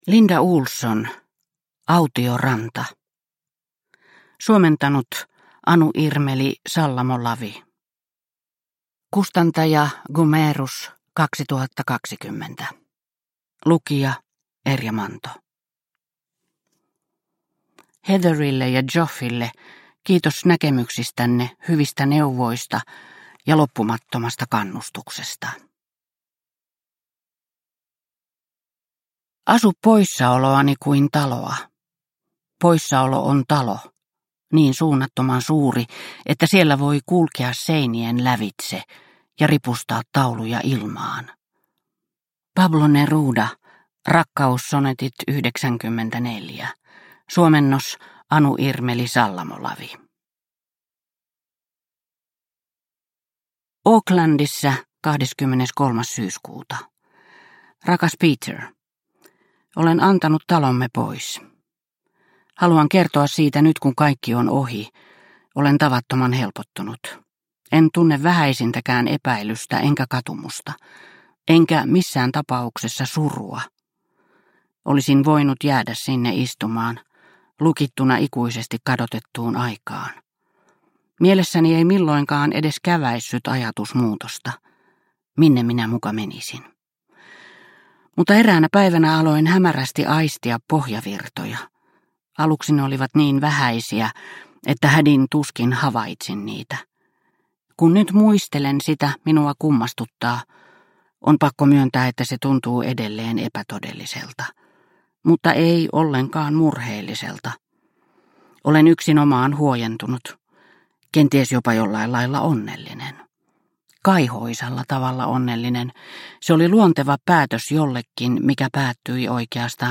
Autio ranta – Ljudbok – Laddas ner